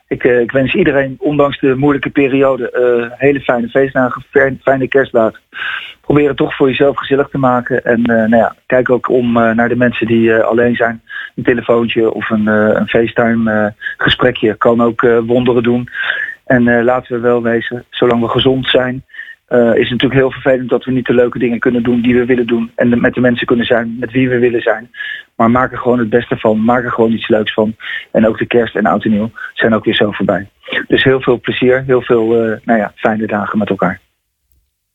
In deze podcast een kerstboodschap van de burgemeester voor alle Capellenaren.